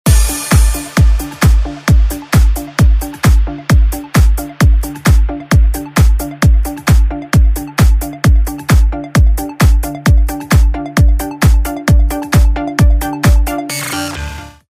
رینگتون پرانرژی و بیکلام